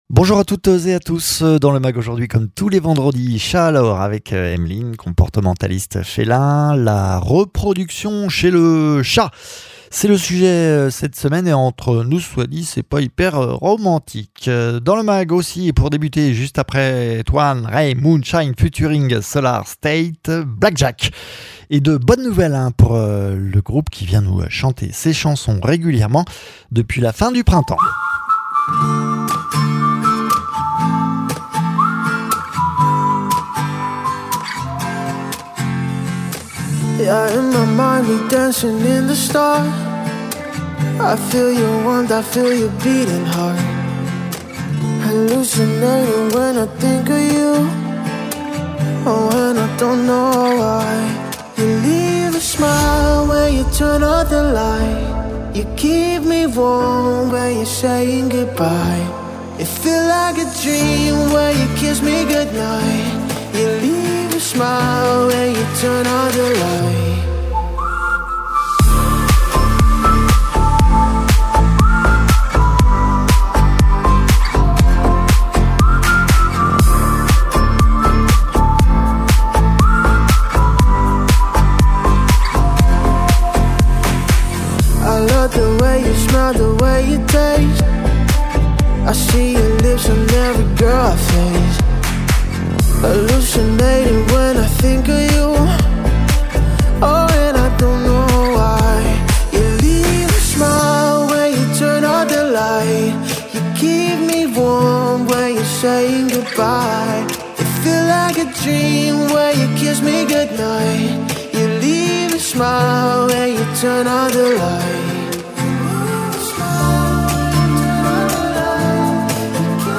Le groupe Black Jacques de retour dans nos studios avec une nouvelle chanson et une très bonne nouvelle, dans le mag également ’’Chat Alors !’’ cette semaine le thème de la reproduction chez le chat